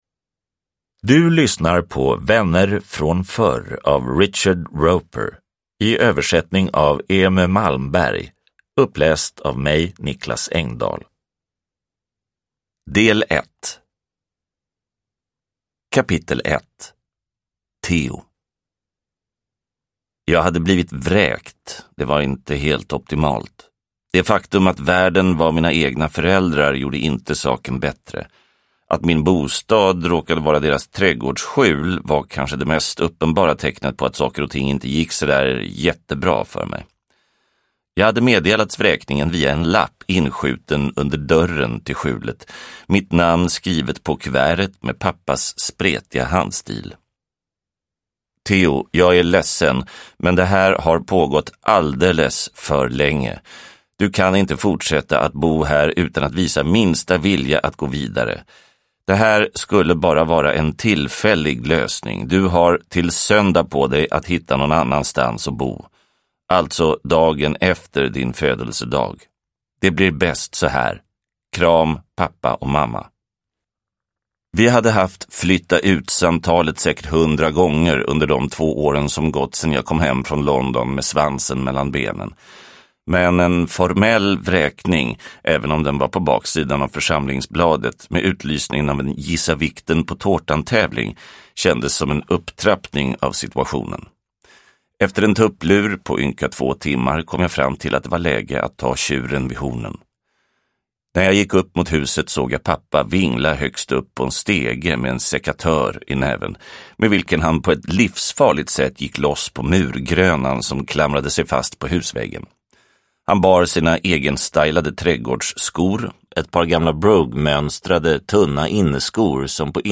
Vänner från förr – Ljudbok – Laddas ner